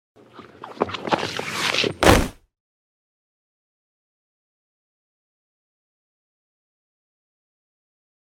Человек подскользнулся